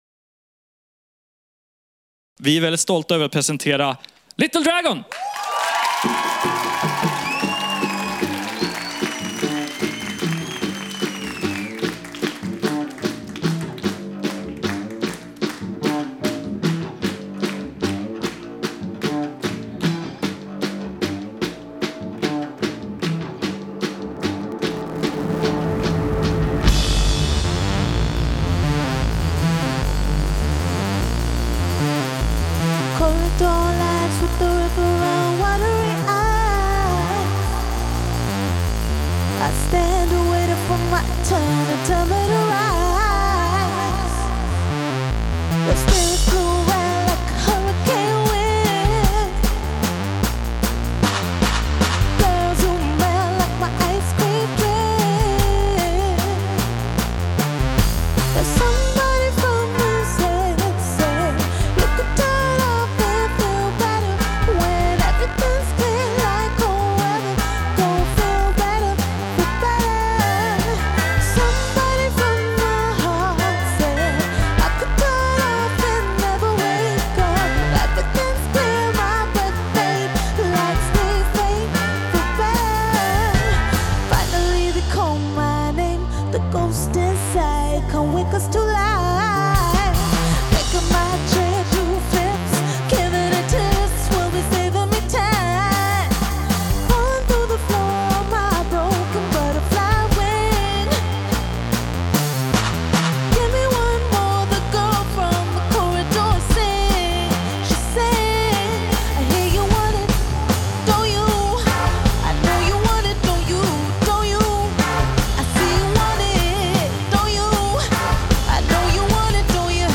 live from Radio Hall in Stockholm on May 28th.
Fronted by the sultry/soulful/breezy vocals
Electro-Indie with Soul.
Swedish Indie/Pop band